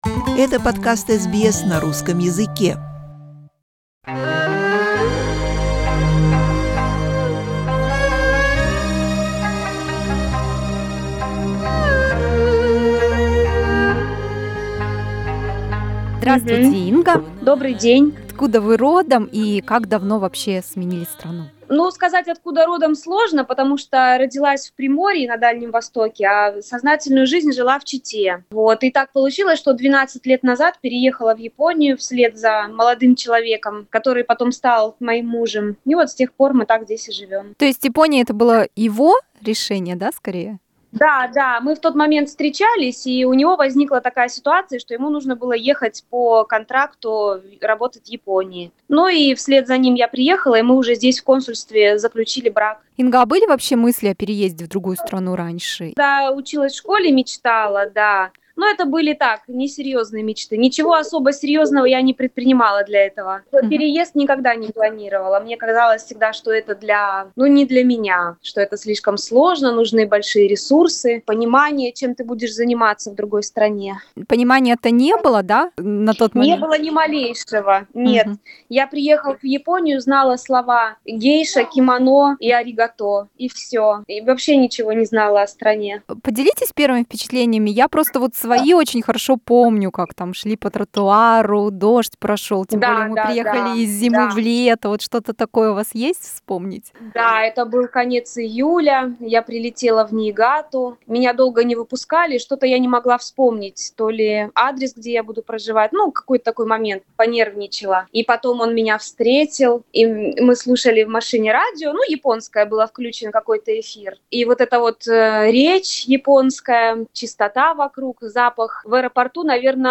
Each immigrant story is unique in its own way, especially when it comes to immigration to different countries. In our new series of interviews we talk to the Russian immigrants from different countries and through their personal stories learn more about other parts of the world.